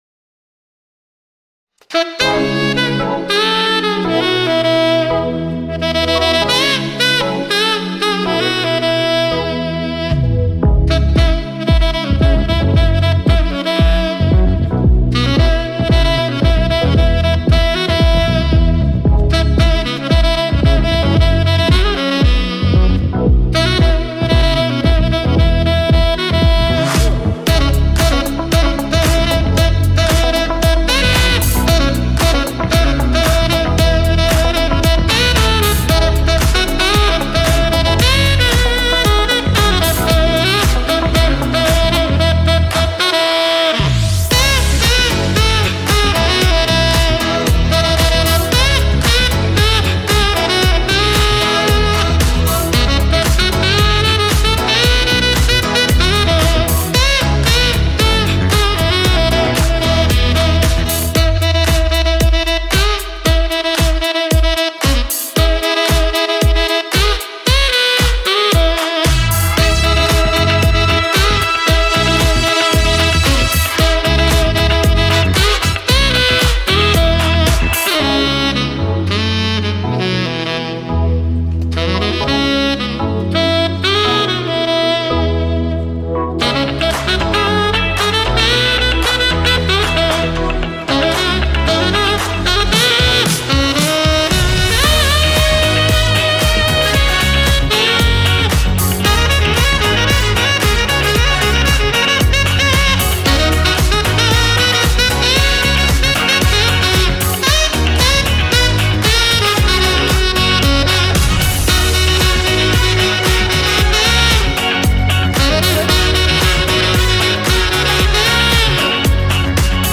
پلی لیست بهترین آهنگ های ساکسوفون (بی کلام)
Saxophone